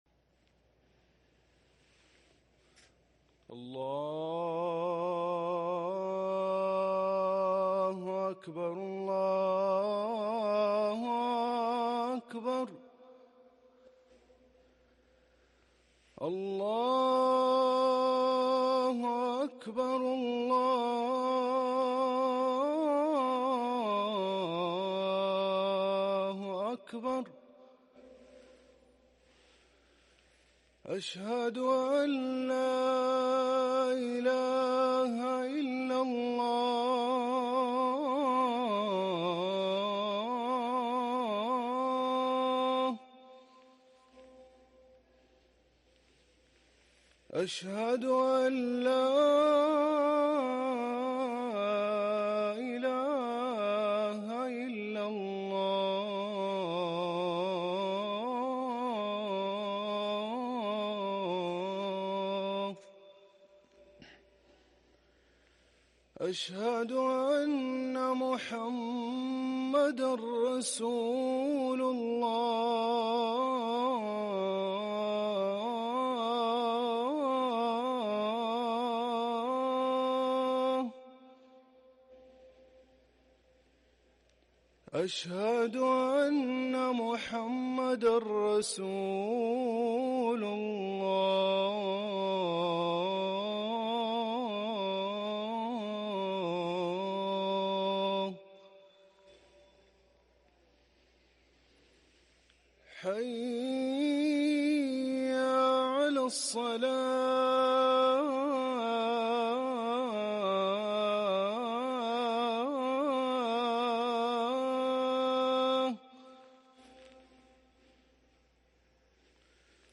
اذان الفجر